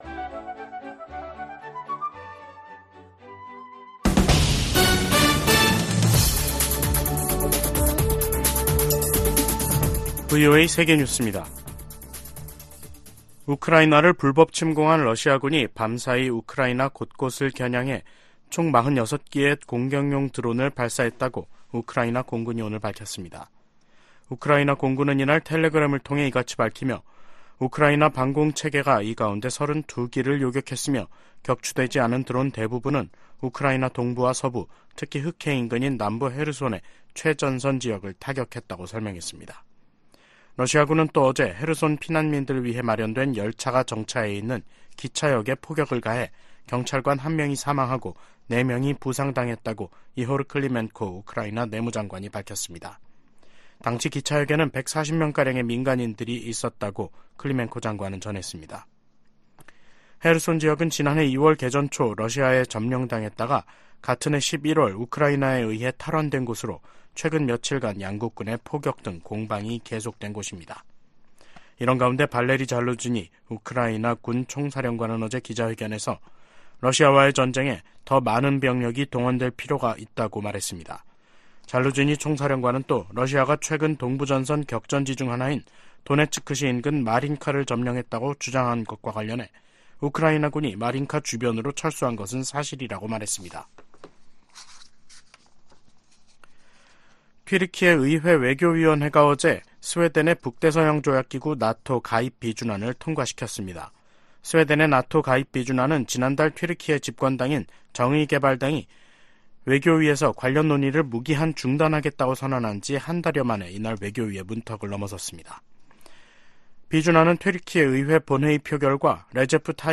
VOA 한국어 간판 뉴스 프로그램 '뉴스 투데이', 2023년 12월 27일 2부 방송입니다. 팔레스타인 무장정파 하마스가 북한산 무기를 다량으로 들여왔다고 이스라엘 방위군(IDF) 대변인이 확인했습니다. 올해 미 의회에서 발의된 한반도 외교안보 관련 법안 가운데 최종 처리된 안건은 12%에 불과했습니다. 북한 노동당 전원회의가 시작된 가운데 김정은 국무위원장은 올해를 ‘위대한 전환의 해’라고 자찬했습니다.